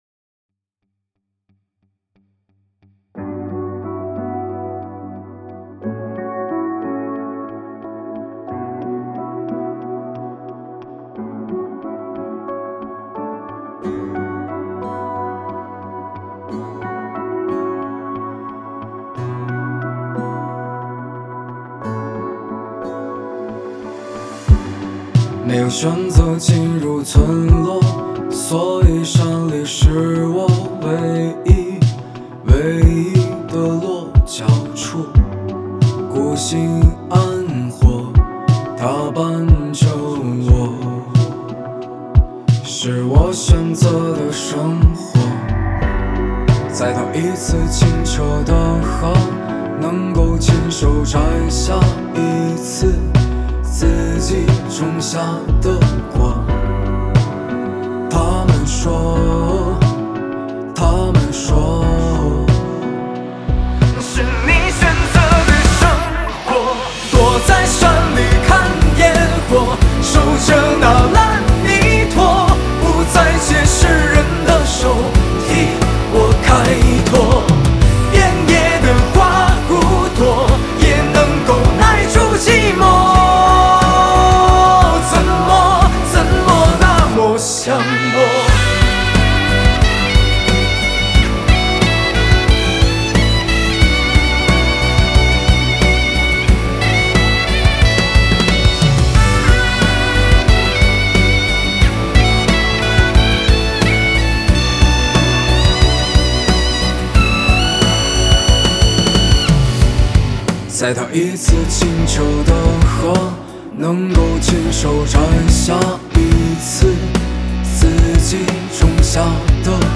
Ps：在线试听为压缩音质节选，体验无损音质请下载完整版
吉他
唢呐
和声